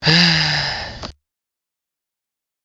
Man Sigh
Man Sigh is a free sfx sound effect available for download in MP3 format.
yt_XTMyD-ldYiA_man_sigh.mp3